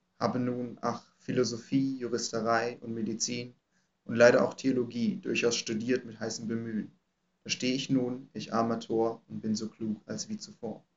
Die Aufnahmen wurden in verschiedenen Abständen aufgenommen.
Ein Hinweis: Die Aufnahmen wurden in einem akustisch gut ausgestatteten Videokonferenzraum aufgenommen.
Abstand 200 cm - Mikrofon auf Sprecher gerichtet
200cm zugewandt als Originalaufnahme © vcc